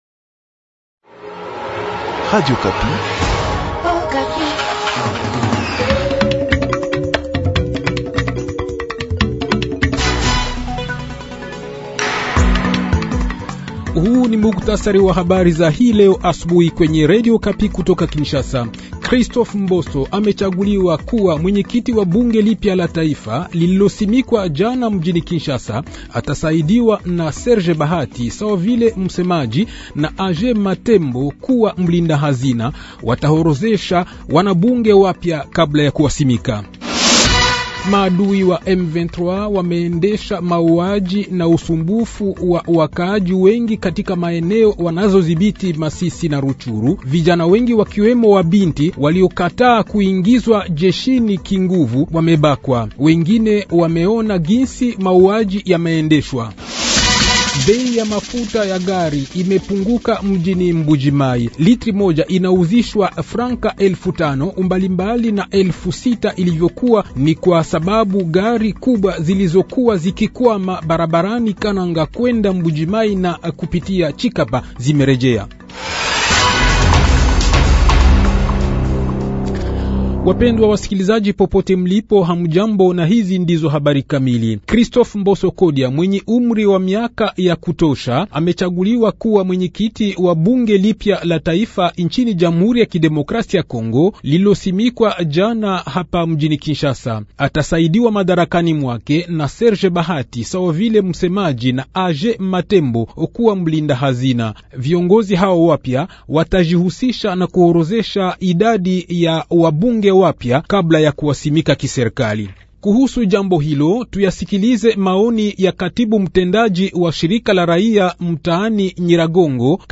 Journal Matin
Le journal Swahili de 5 h, 30 Janvier 2024